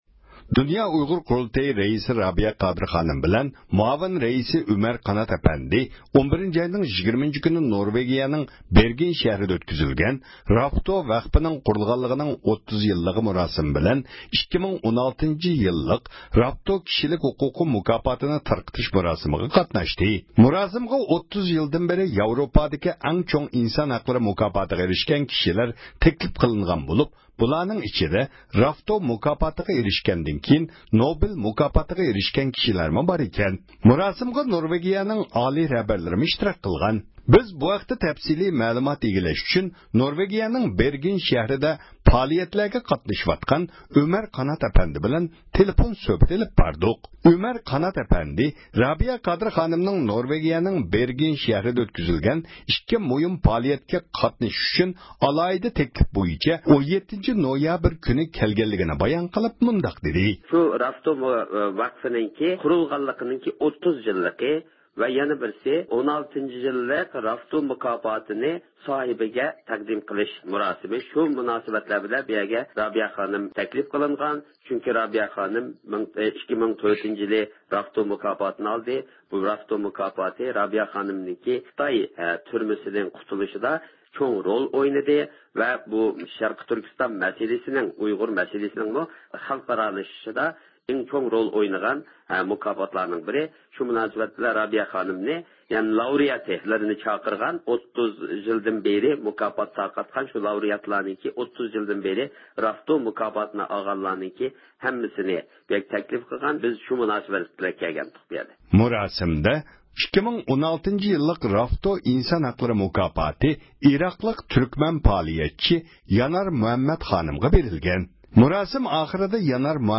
تېلېفون سۆھبىتى